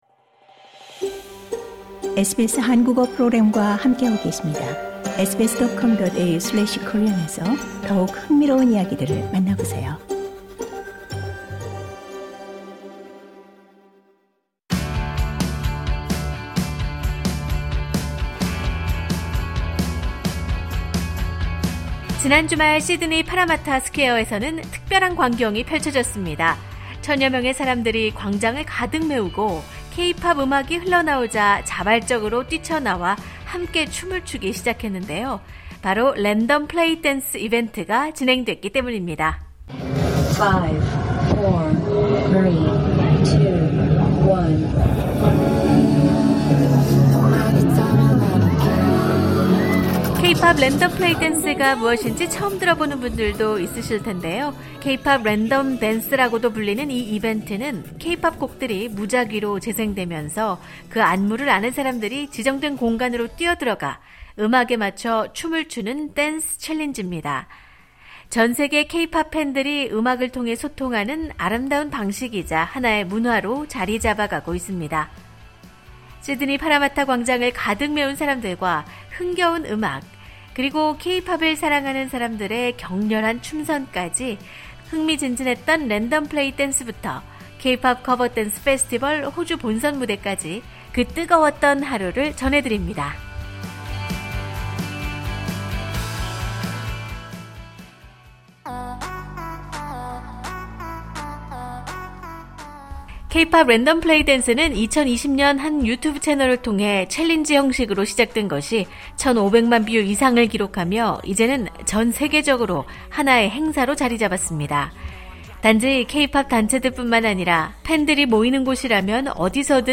지난 주말, 시드니 파라마타에서 열린 K-팝 커버 댄스 본선 대회와 함께 사전 랜덤 플레이 댄스 현장의 생생함을 전합니다.